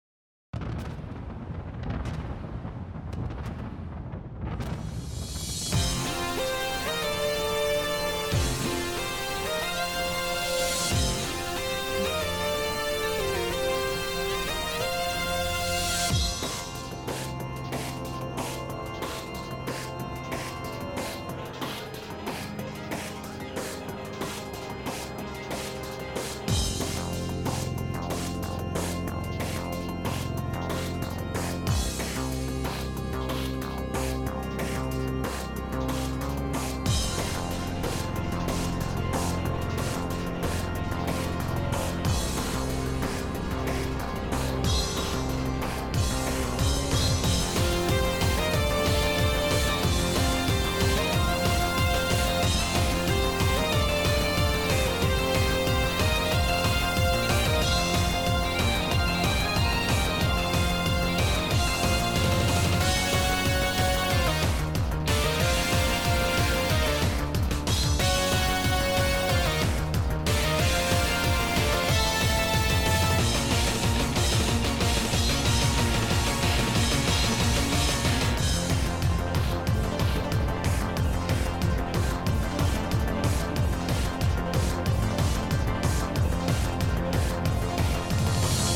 Application of EBU R 128 to all BGM